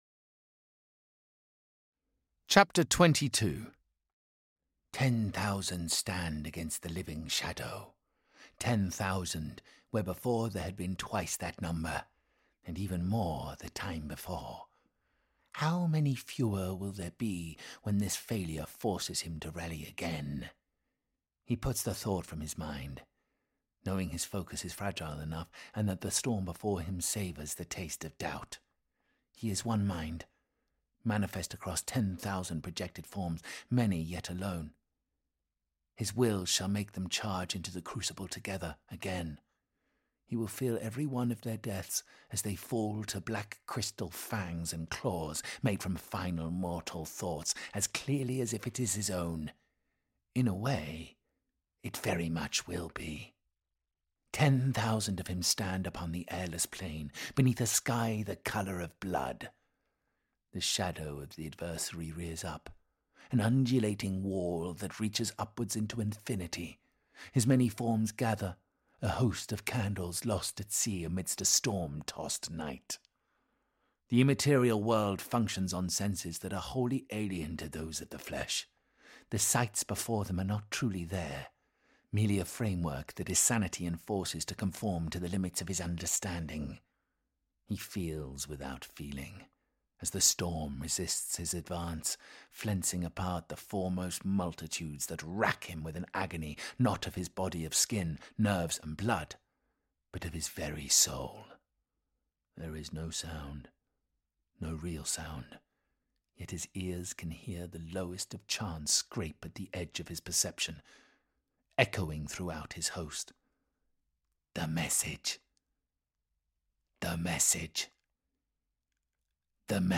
Index of /Games/MothTrove/Black Library/Warhammer 40,000/Audiobooks/Space Marine Conquests/Space Marine Conquests (Book 04) - Of Honour and Iron